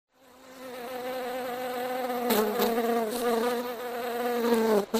Bee Buzzing
Bee Buzzing is a free animals sound effect available for download in MP3 format.
043_bee_buzzing.mp3